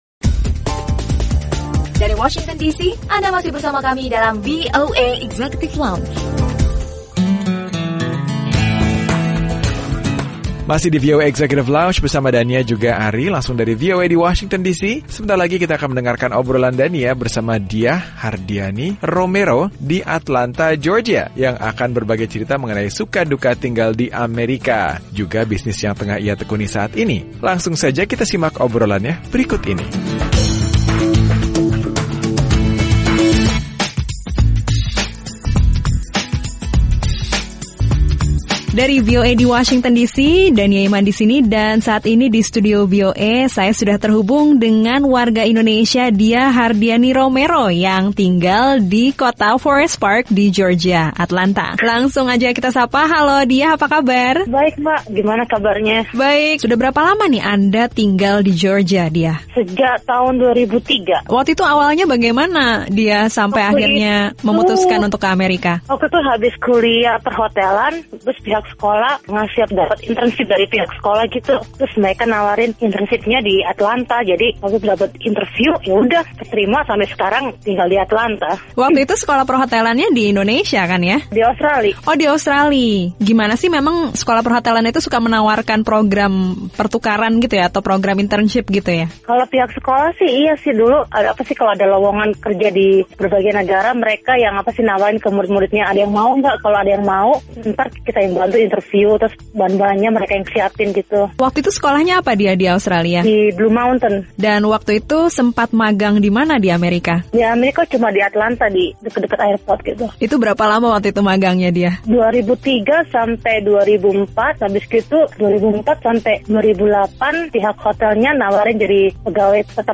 Obrolan bersama perantau Indonesia di Atlanta - Georgia